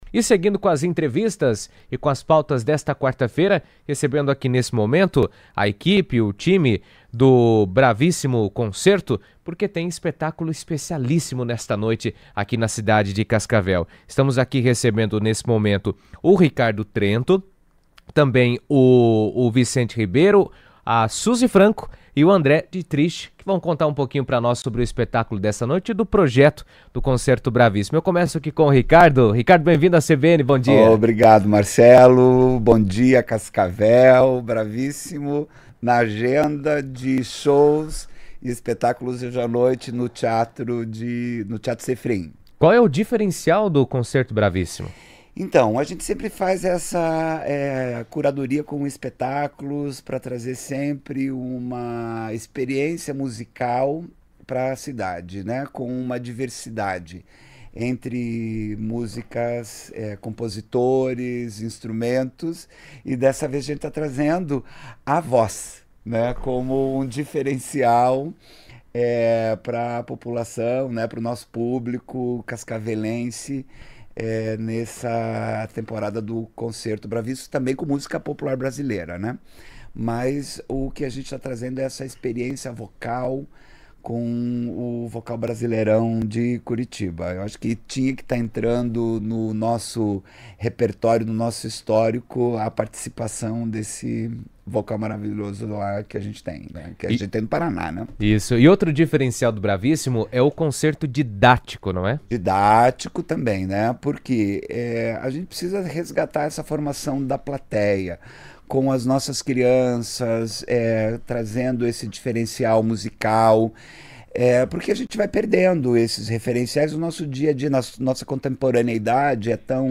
O espetáculo Vocal Brasileirão – Brasil Gongá, apresentado pelo Bravíssimo Concertos em Cascavel, celebra a diversidade e a riqueza da música brasileira com performances vocais e instrumentais. Em entrevista à CBN